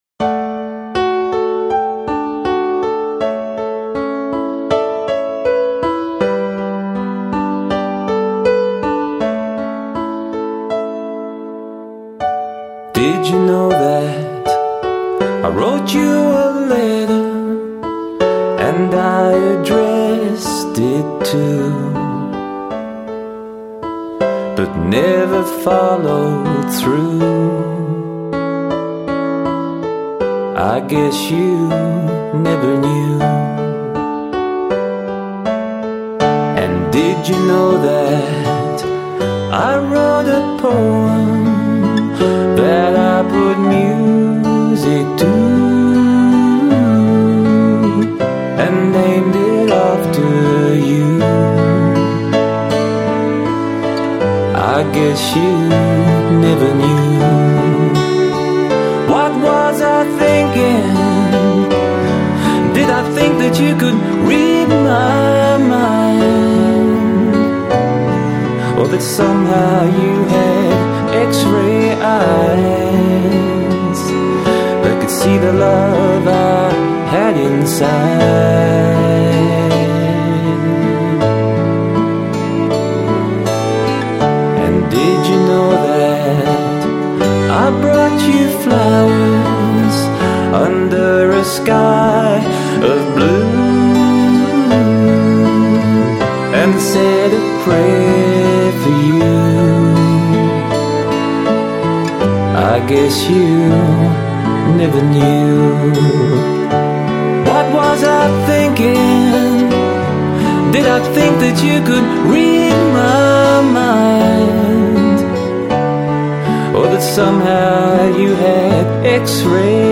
(country)